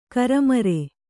♪ karamare